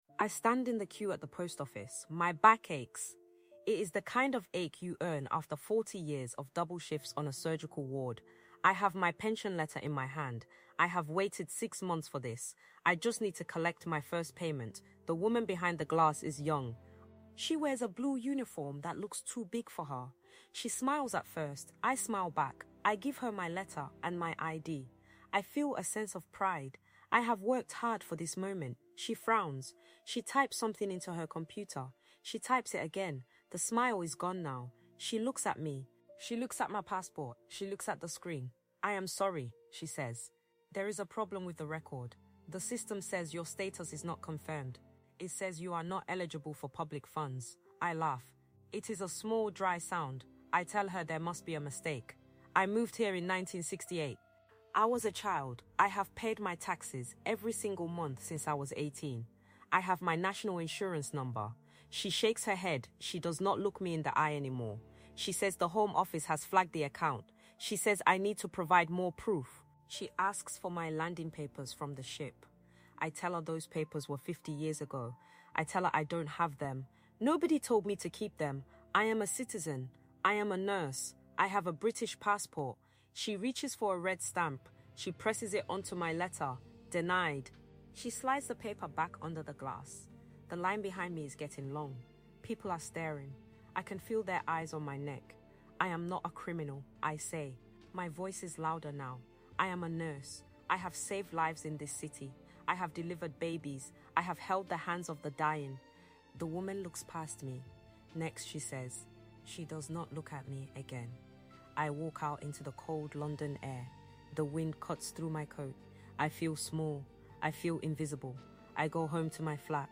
Experience a gripping, first-person narrative in this episode of THE TRIALS OF WOMAN, a podcast dedicated to the high-stakes survival stories of women across the globe.